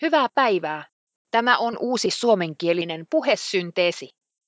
Yhdistelmälisenssi sisältää DialoQ Taivuttimen ja 4kpl aikuisten puheääniä.